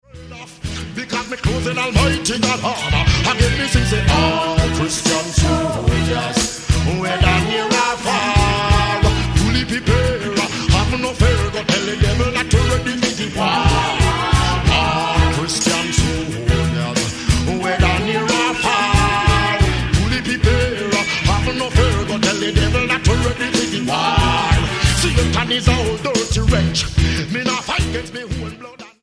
10445 Style: Reggae Approach